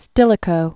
(stĭlĭ-kō), Flavius c. 365-408 AD.